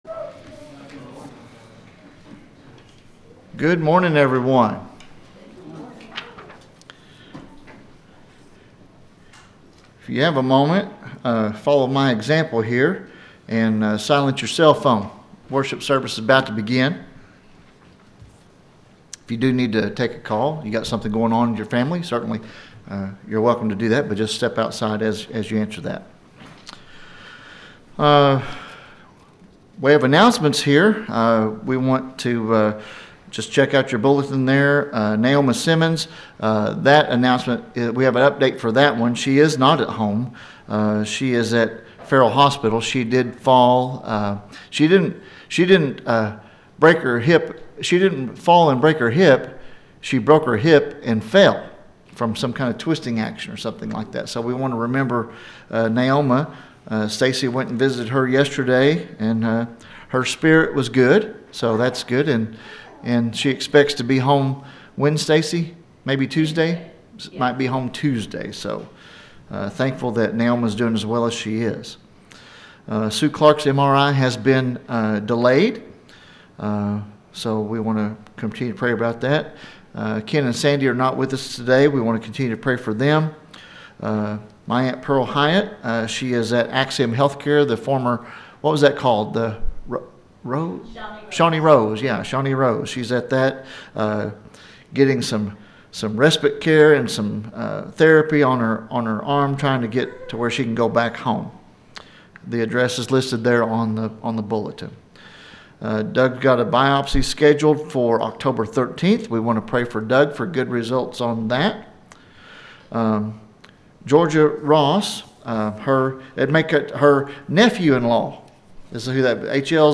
SUNDAY AM WORSHIP
The sermon is from our live stream on 8/24/2025